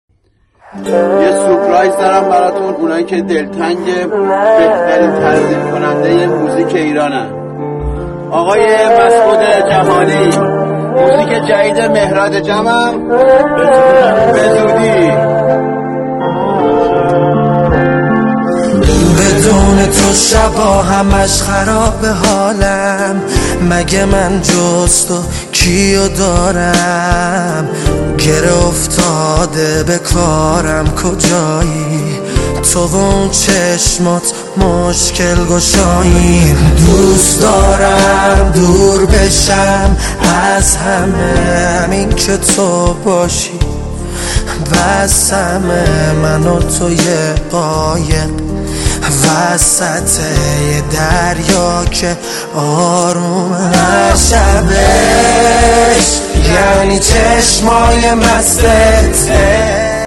آهنگ جدید و غمگین